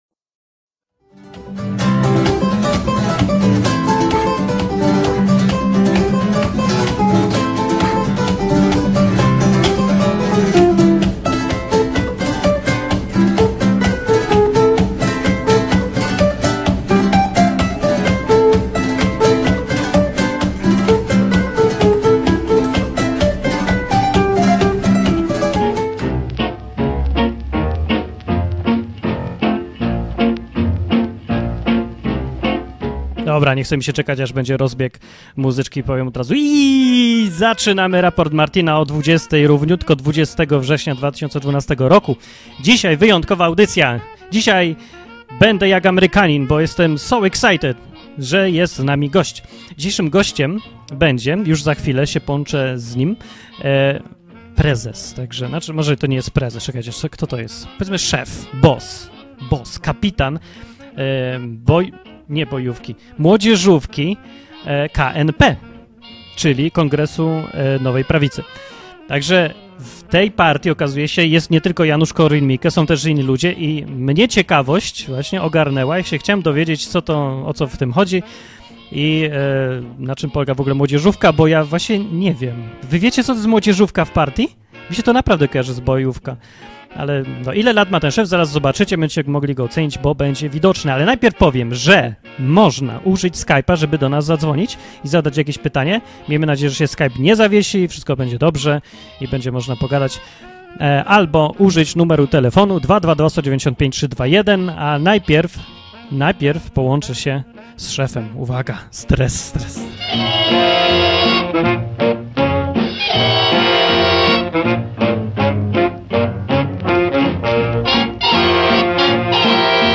program satyryczno-informacyjny, który ukazywał się co tydzień w radiu KonteStacja